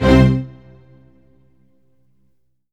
Orchestral Hits
ORCHHIT C3-R.wav